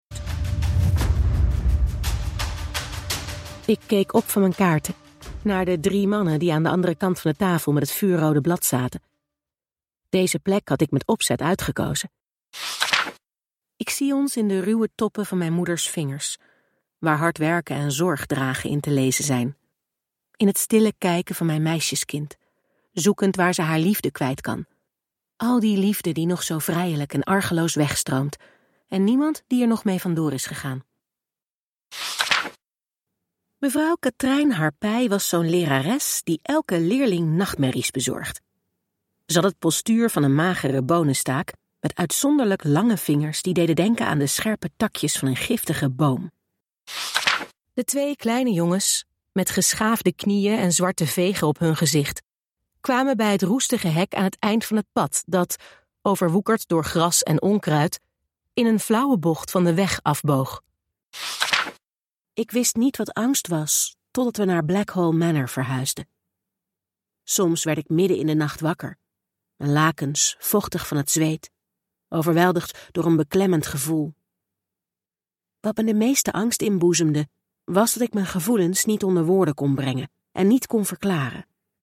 Mijn Demo’s & Showreel
Luisterboeken
2023-audioboeken-demo-mp3.mp3